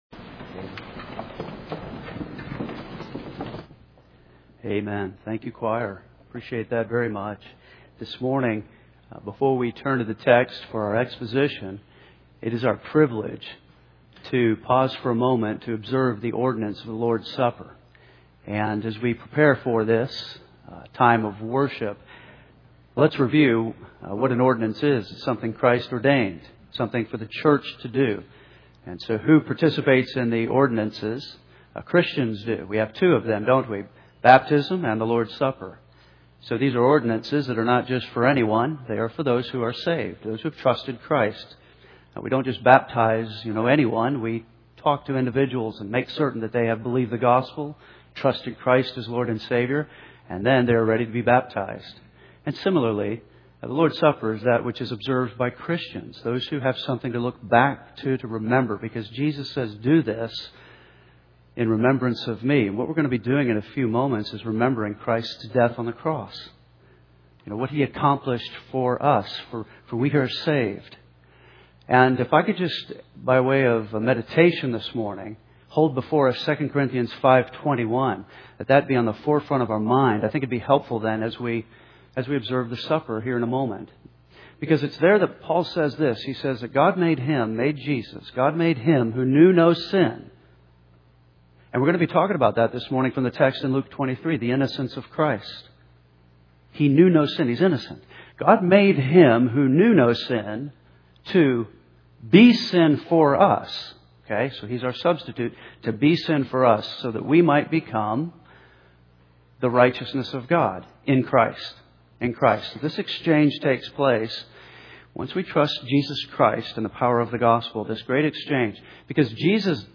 This is the best way to preach, teach, and learn the Word of God.